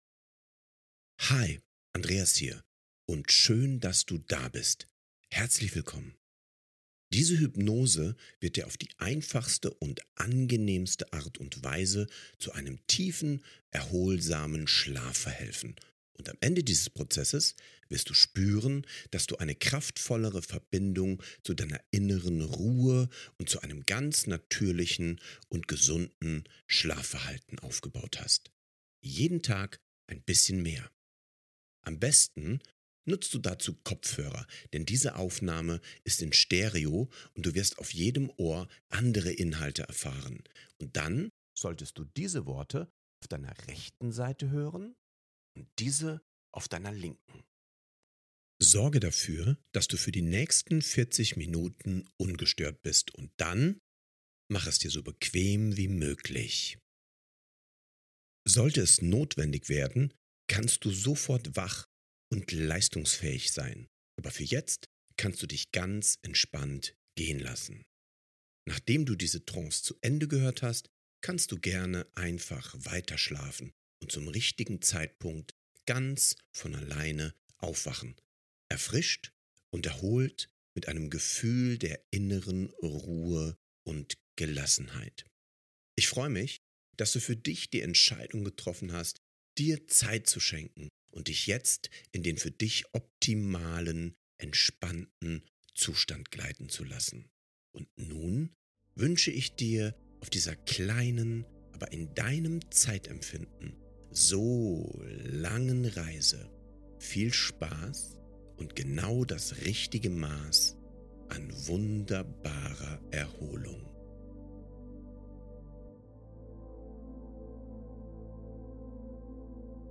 Beschreibung vor 1 Jahr Herzlich willkommen zu dieser Hypnose zum Einschlafen – für tiefen Schlaf & erfrischtes Aufwachen!